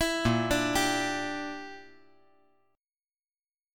A#6b5 Chord
Listen to A#6b5 strummed